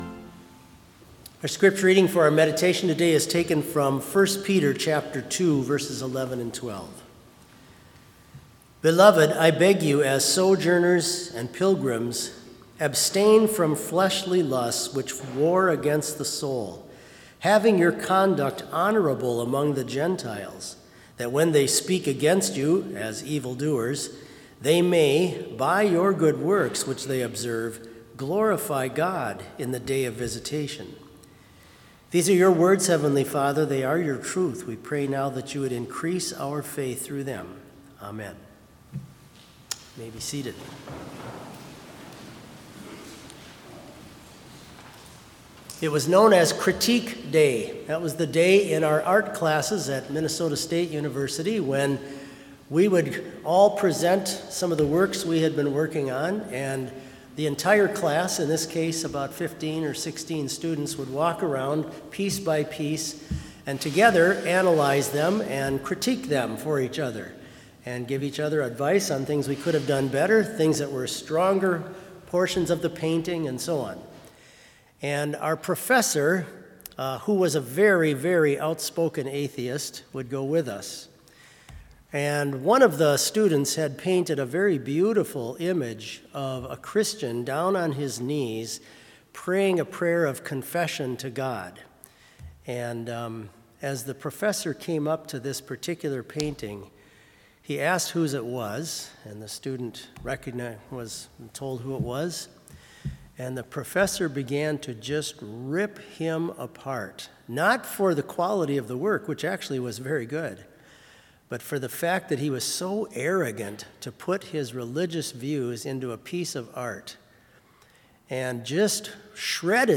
Complete service audio for Chapel - March 14, 2022